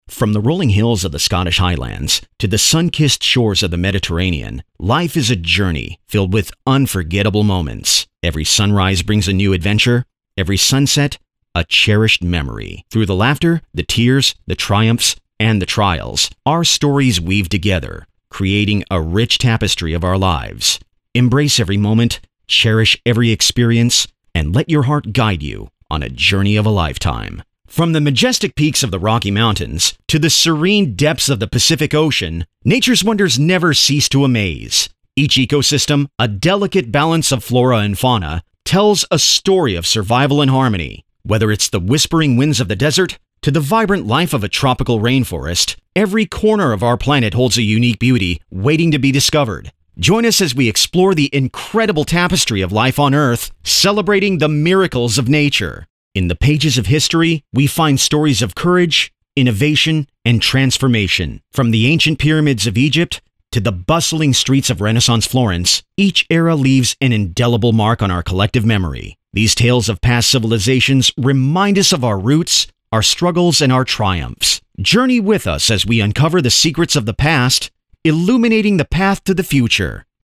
Versatile, Engaging, and Professional Voiceover Talent
Narration Demo
With over 25 years in media, I bring a rich, dynamic voice that elevates brands and captivates audiences.